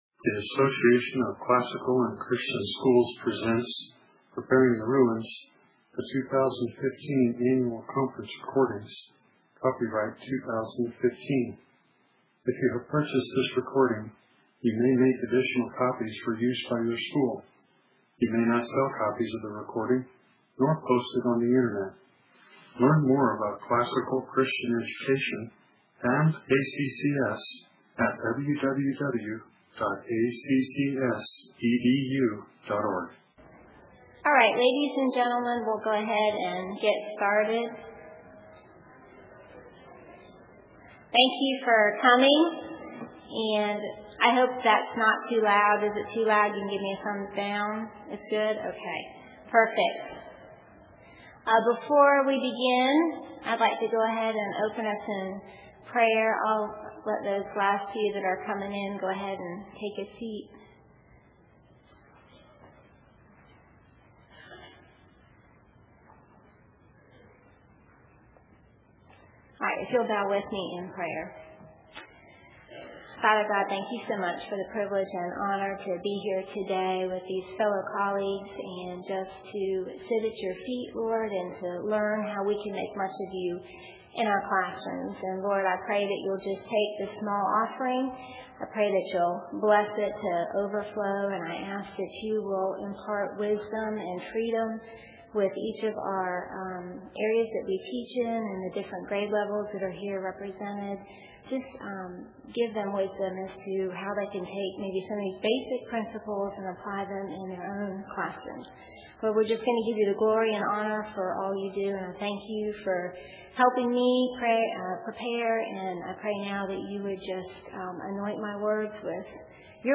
2015 Workshop Talk | 0:52:23 | All Grade Levels, Bible & Theology, Virtue, Character, Discipline